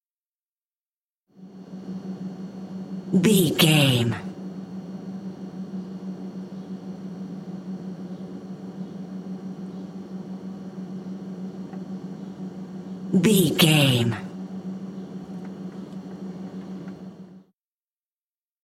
Refrigerator ext 271
Sound Effects
house kitchen